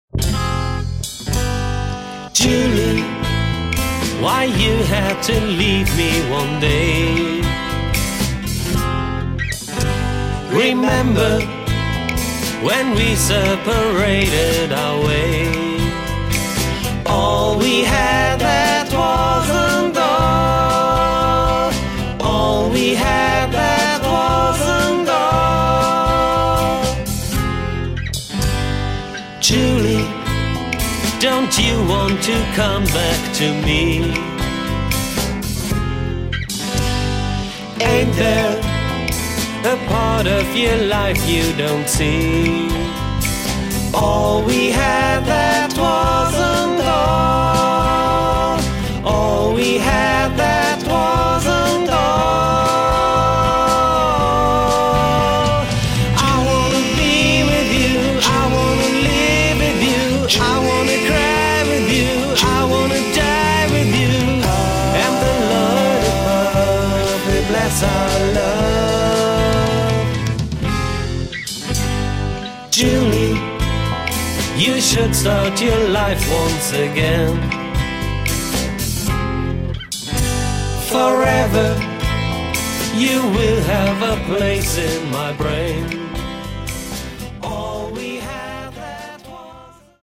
ld-voc., rh-g.
voc., bg.
voc., perc.